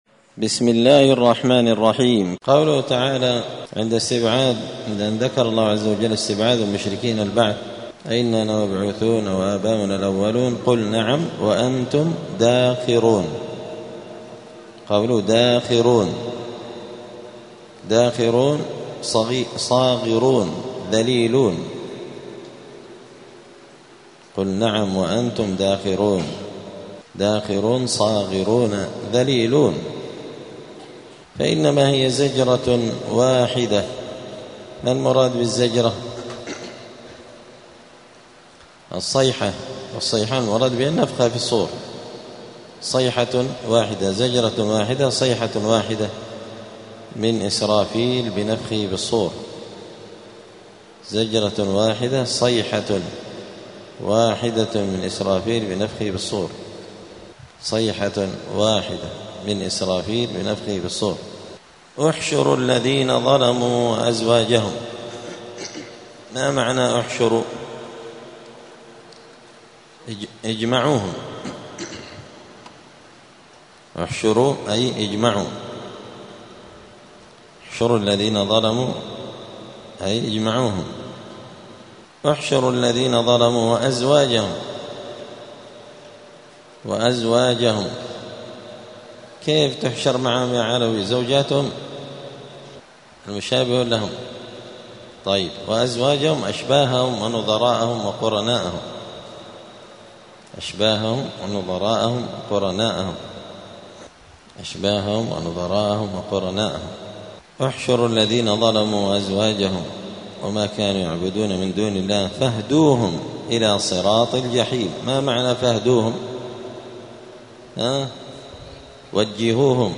*(جزء يس سورة الصافات الدرس 281)*